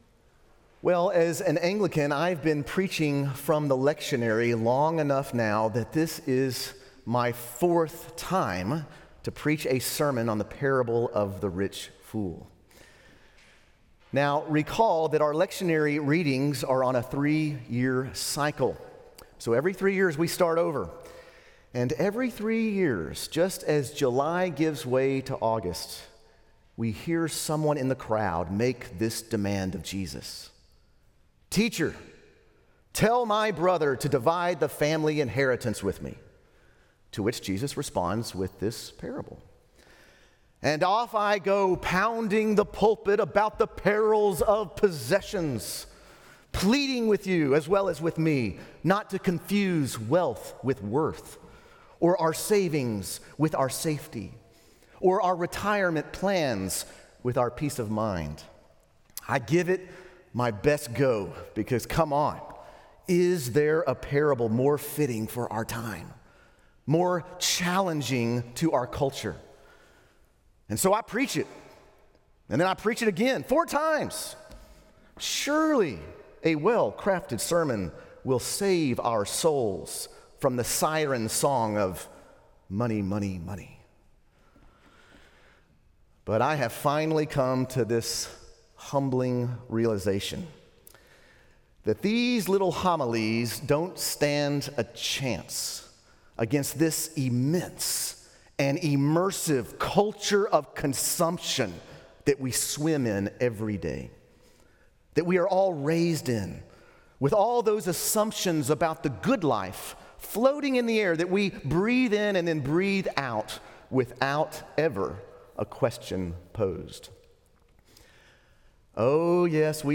Just Another Sermon
Just Another Sermon.m4a